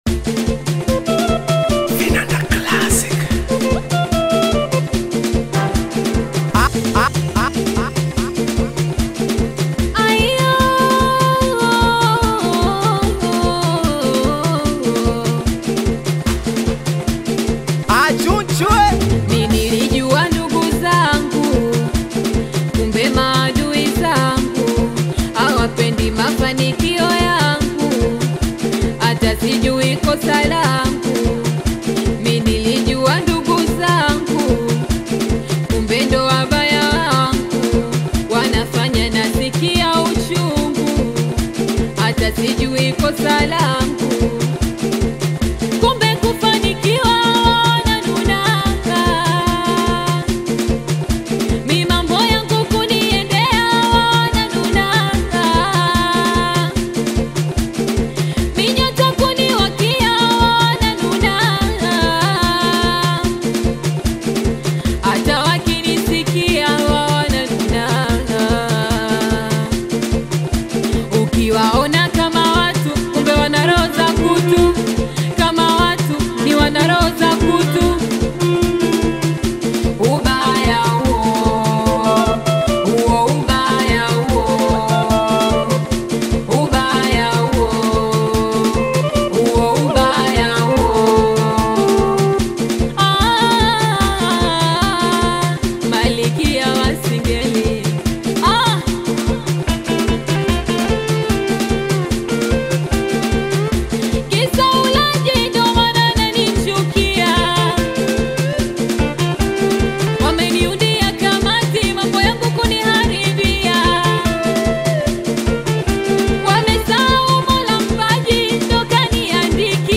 Singeli music track
Tanzanian Bongo Flava